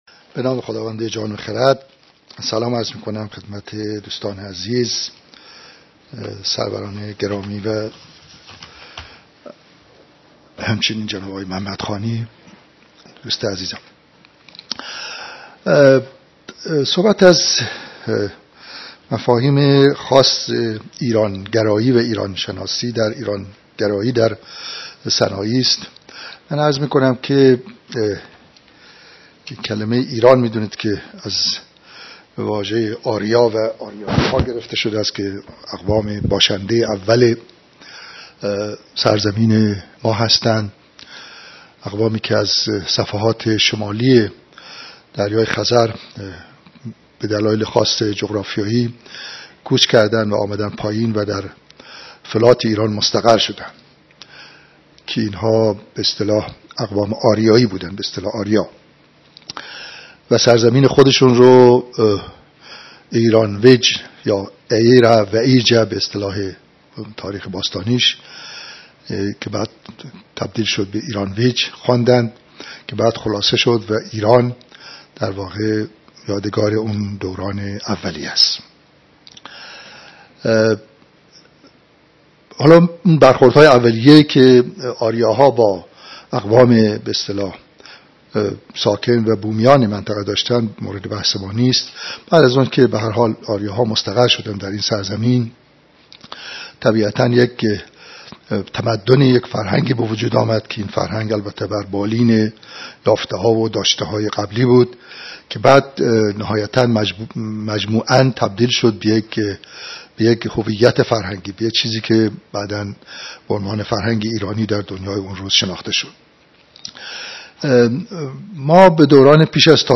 فایل شنیداری درس‌گفتار «ایرانیات سنایی»